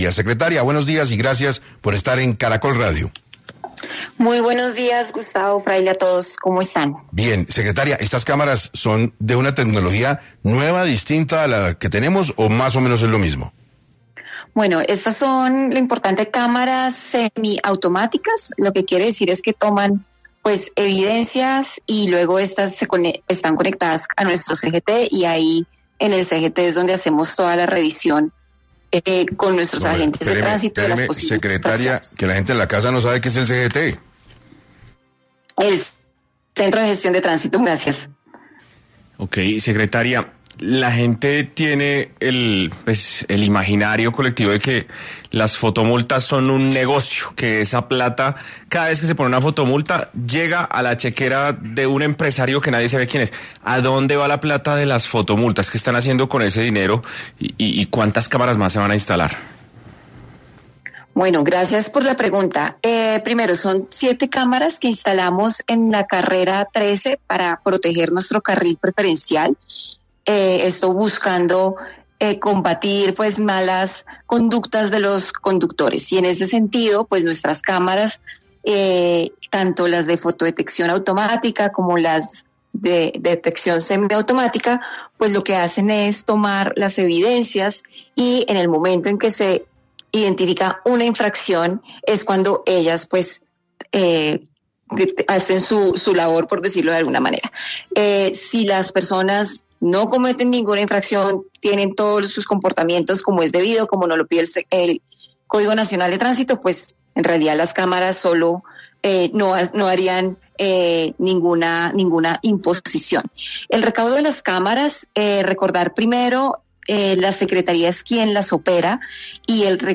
La secretaria de Movilidad de Bogotá, Claudia Díaz, estuvo en 6AM para profundizar en estas nuevas directrices.